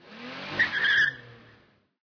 snd_burnout.ogg